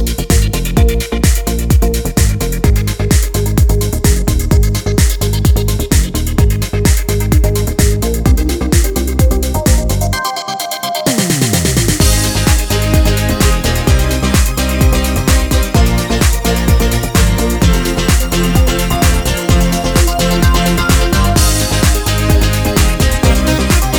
Dance Mix Dance 4:13 Buy £1.50